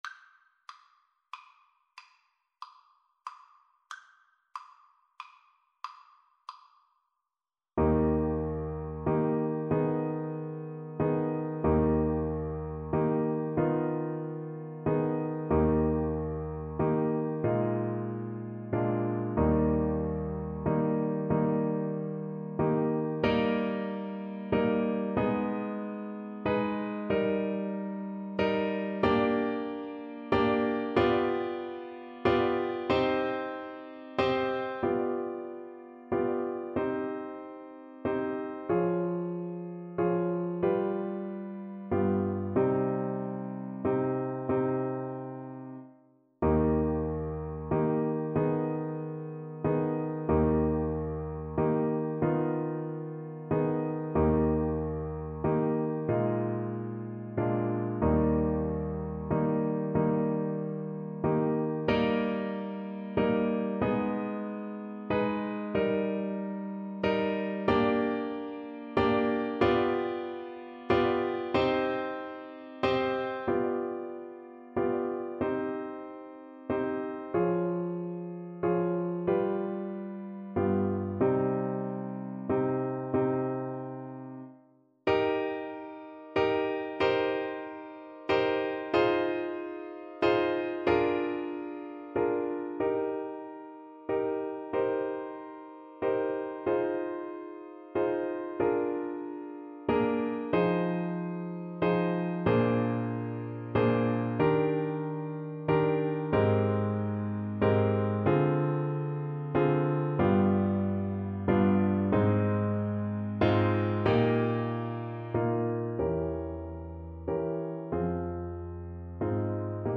Eb major (Sounding Pitch) (View more Eb major Music for Violin )
6/8 (View more 6/8 Music)
Classical (View more Classical Violin Music)